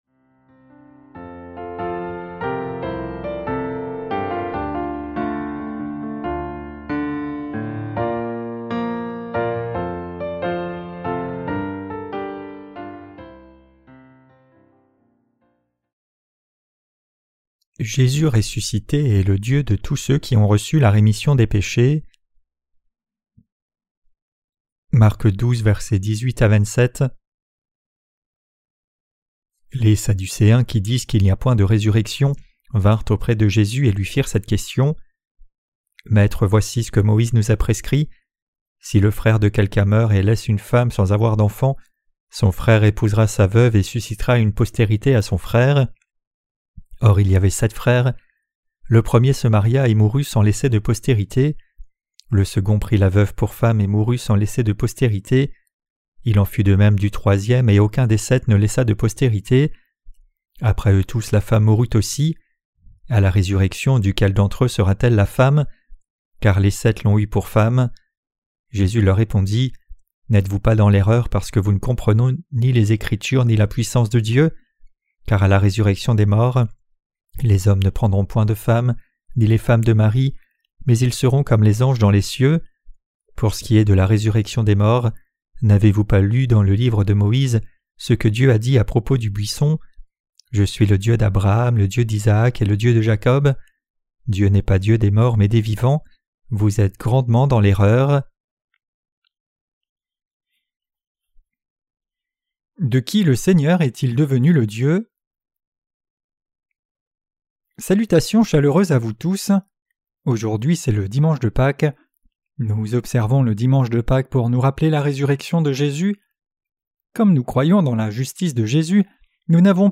Sermons sur l’Evangile de Marc (Ⅲ) - LA BÉNÉDICTION DE LA FOI REÇUE AVEC LE CŒUR 4.